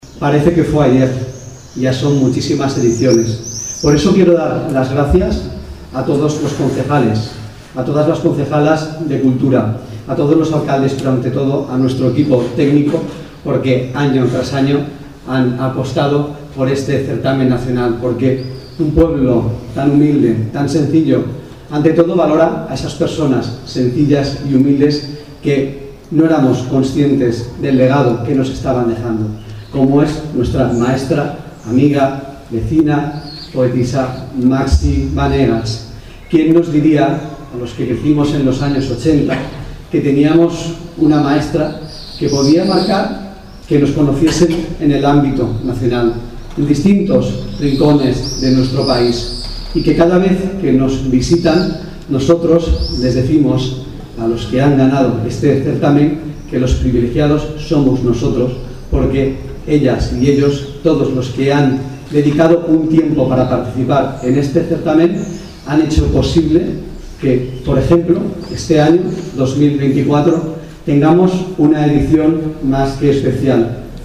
La Casa de Don Pedro ha sido el escenario que ha acogido la gala de entrega de premios del XXVII Certamen Nacional de Poesía Maxi Banegas y el XVI Concurso de Narrativa Corta, en un ambiente íntimo donde la música, la poesía y el vino se dan la mano.
El alcalde Lázaro Azorín ha alabado la figura de la poetisa y el legado que ha dejado en el municipio y sus vecinos.